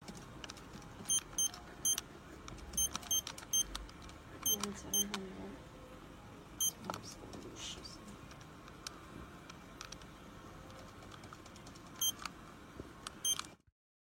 Tiếng bíp bíp khi nhấn nút máy tính số, tính toán, máy tính Casio bỏ túi…
Tiếng Bấm nút máy tính toán, tính số, máy tính Casio bỏ túi… Anh làm nghề này được bao lâu rồi… meme sound effect
Thể loại: Tiếng đồ công nghệ
Description: Tiếng “bíp bíp” đặc trưng vang lên khi nhấn nút trên máy tính Casio bỏ túi hay máy tính điện tử là âm thanh quen thuộc của thao tác tính toán. Hiệu ứng âm thanh gợi cảm giác chính xác, nhịp nhàng và công nghệ.
tieng-bip-bip-khi-nhan-nut-may-tinh-so-tinh-toan-may-tinh-casio-bo-tui-www_tiengdong_com.mp3